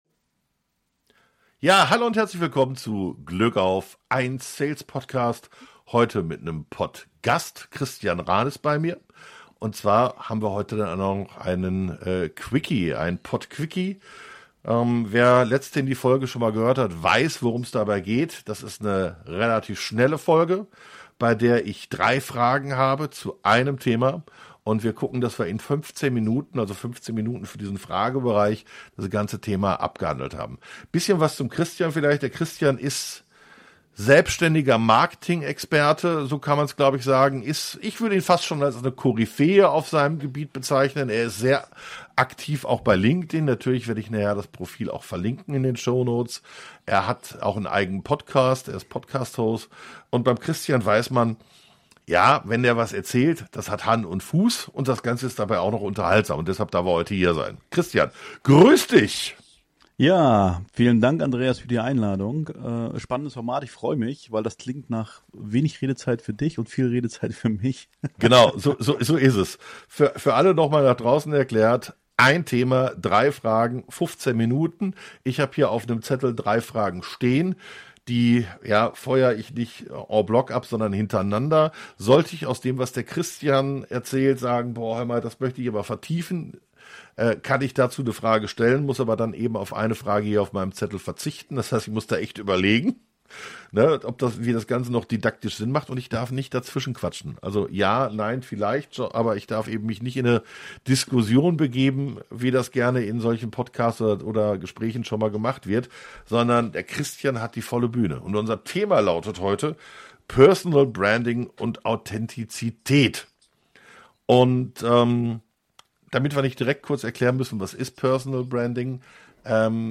1 Thema, 3 Fragen, 15 Minuten - so lautet das Motto des Pott-Quickie.